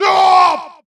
hugeLogCutVoice.wav